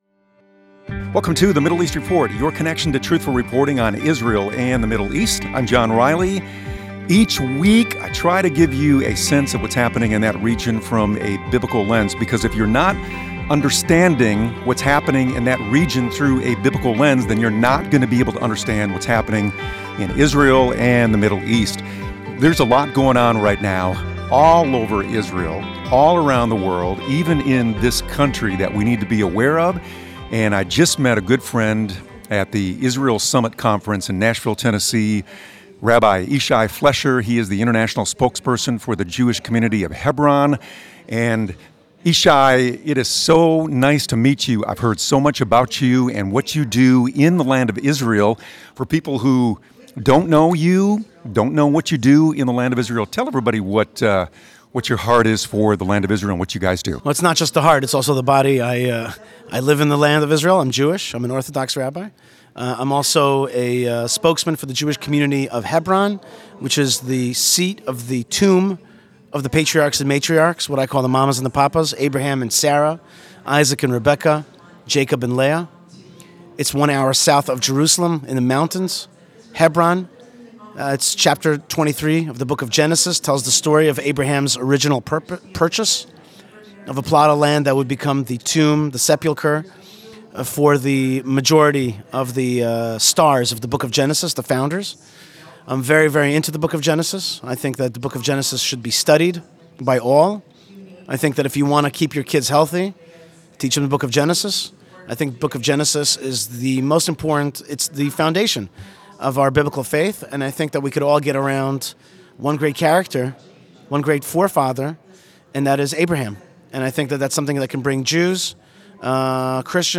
Show Notes Special Guest